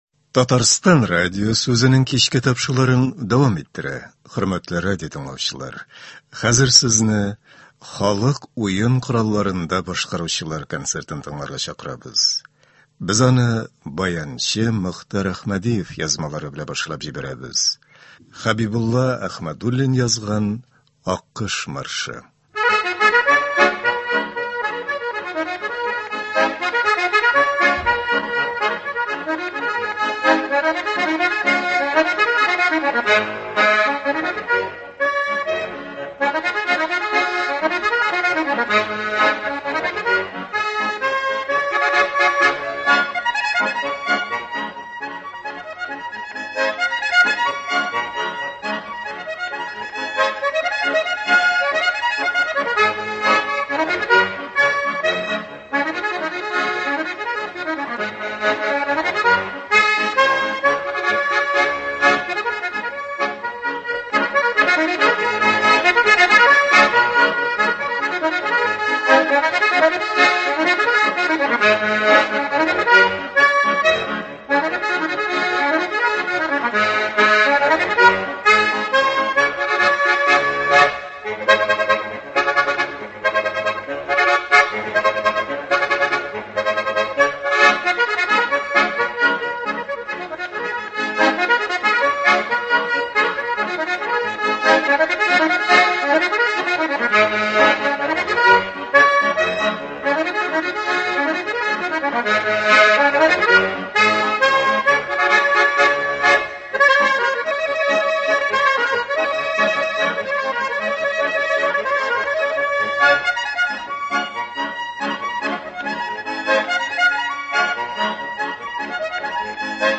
Халык уен коралларында башкаручылар концерты.